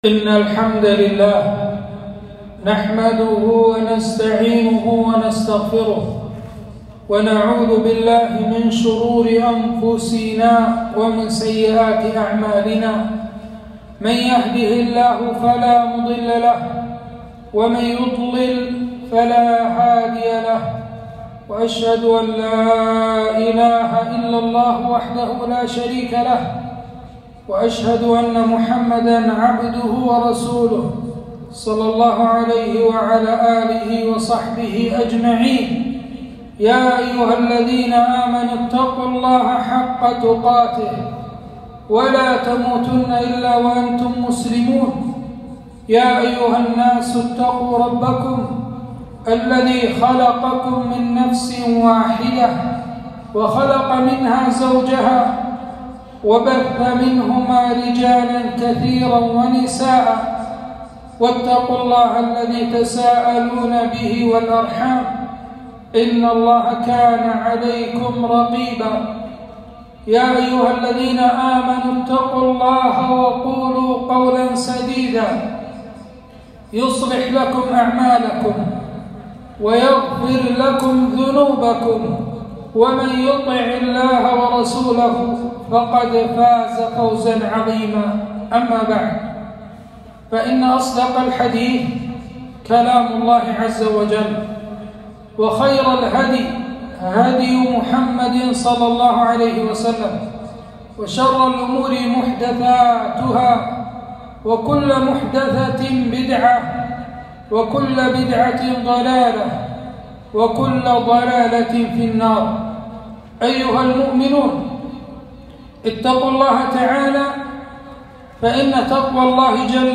خطبة - فضل الصحابة رضي الله عنهم